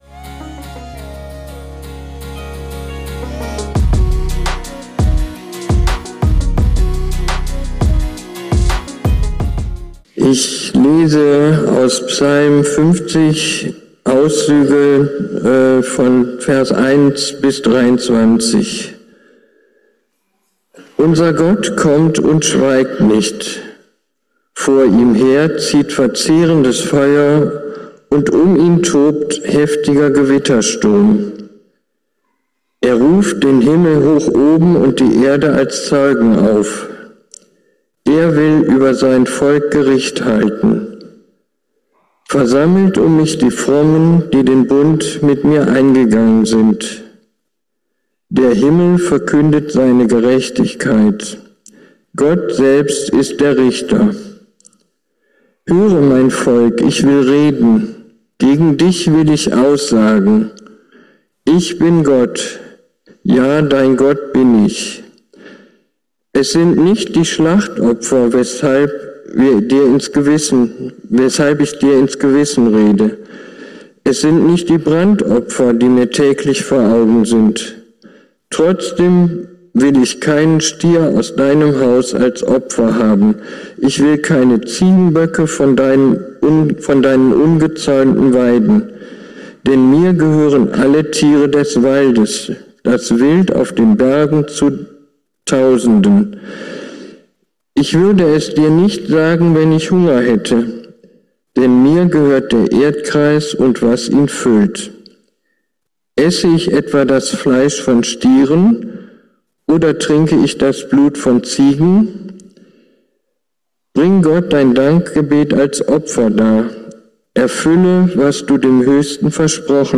Aber die kommt nicht von Menschen - sondern Gott hat eine Lösung. Wenn du dich gerade über die Welt und ihre Menschen aufregst - höre dir die Predigt an: Du bist nicht alleine, aber es gibt eine Zukunft.